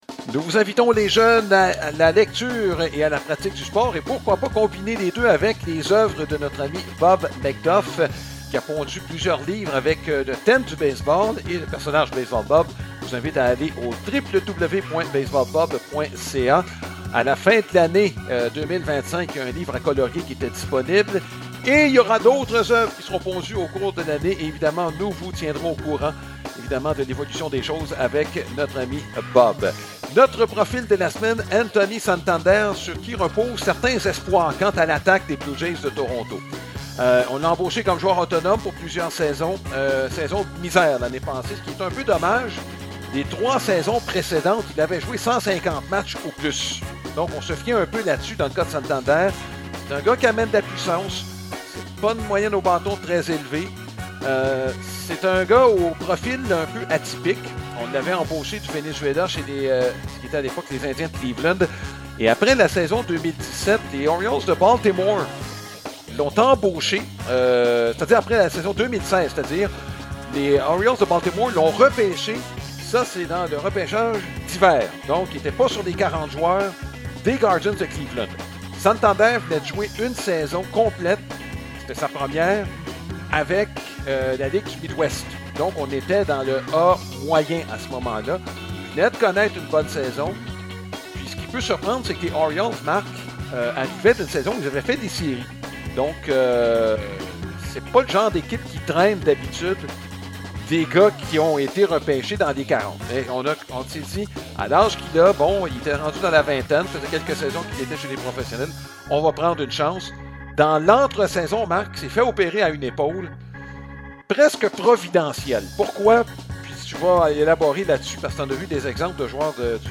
Licence pour usage légal de la musique: Funky Organ par alexshulgin: LNAZYX2HVC